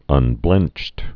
(ŭn-blĕncht)